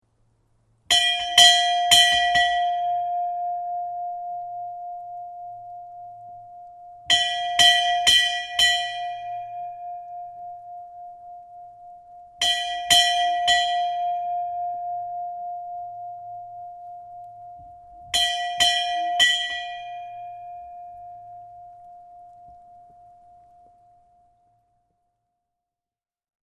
Í kapellunni er ein klukka staðsett undir þakinu framan við útidyrahurð kapellunnar. Á klukkuna stendur letrað IMMANUEL sem þýðir á hebresku Guð er með oss. Klukkan er notuð til að kalla piltana í Vatnaskógi til kvöldstunda í kapellunni en einnig þegar þar fara fram athafnir.